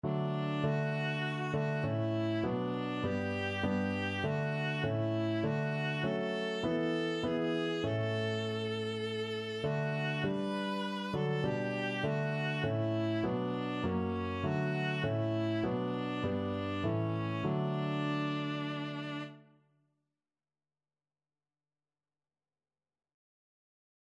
Christmas Christmas Viola Sheet Music While Shepherds Watched Their Flocks
Viola
4/4 (View more 4/4 Music)
D major (Sounding Pitch) (View more D major Music for Viola )